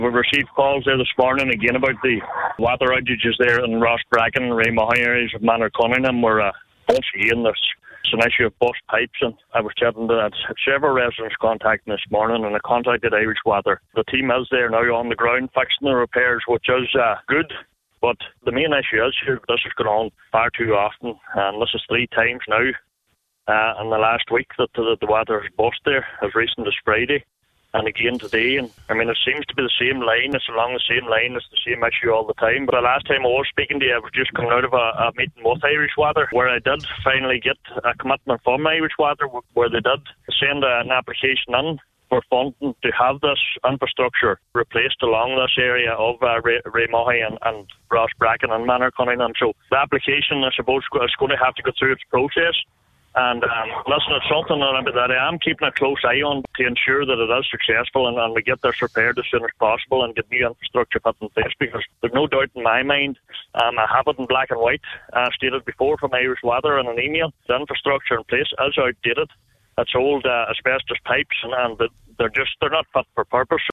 Cllr Donal Mandy Kelly says an application has been lodged to have that line upgraded, and that process must be expedited: